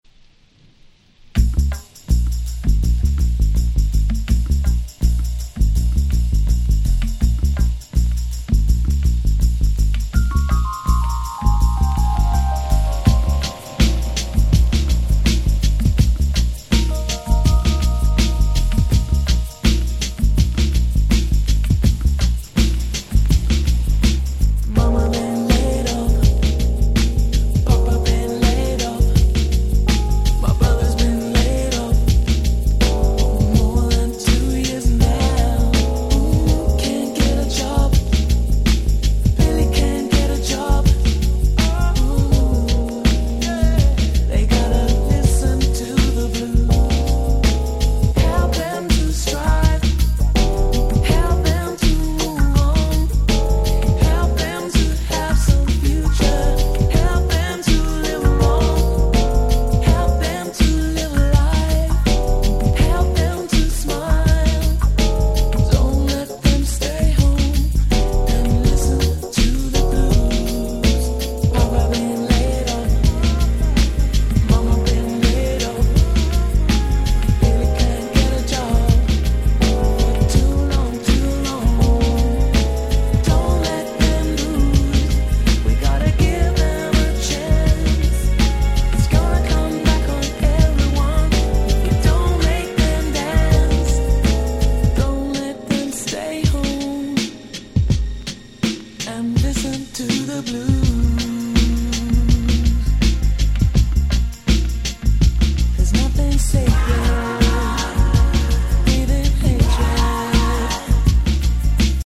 92' Smash Hit UK Soul !!
彼女らしい激ムーディーな大人の1曲。